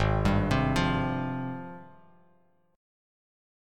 AbM13 Chord
Listen to AbM13 strummed